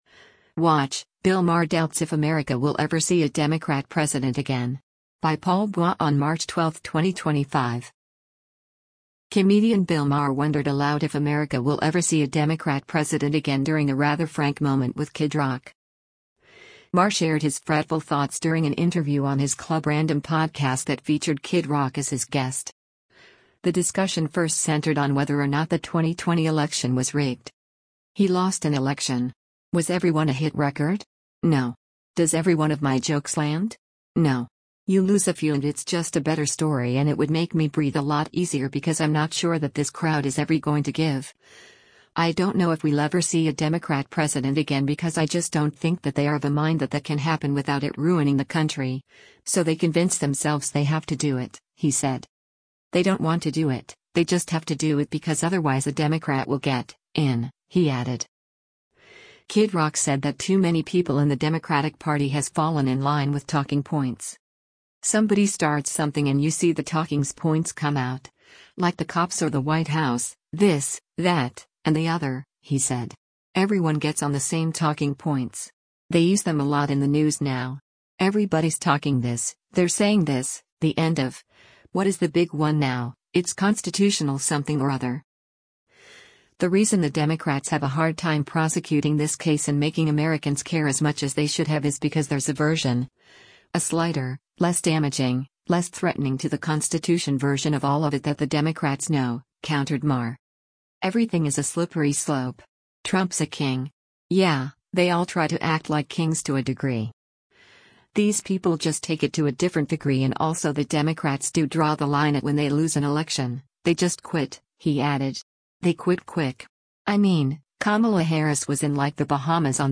Comedian Bill Maher wondered aloud if America will “ever see a Democrat president again” during a rather frank moment with Kid Rock.
Maher shared his fretful thoughts during an interview on his Club Random podcast that featured Kid Rock as his guest. The discussion first centered on whether or not the 2020 election was “rigged.”